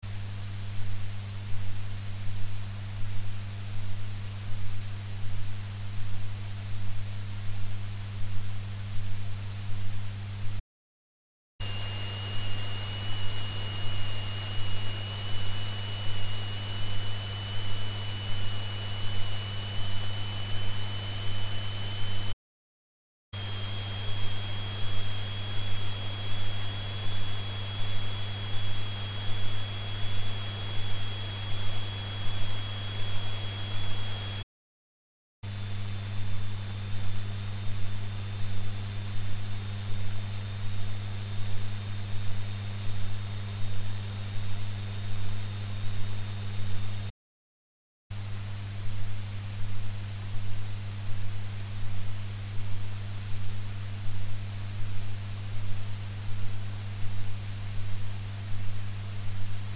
AC駆動，ゲインH，ファンタム48Vで，XLR入力端子の接続状態を変えながら
録音したファイルをPC側で48dB増幅して，ノイズを聴きやすく拡大した．
2,3,4にキーンというノイズ音が聴こえる．
4でノイズ音が小さくなり，5では消えてしまっている．
In1,In2とも同じような結果だが，In1のほうはプツプツというノイズ音がして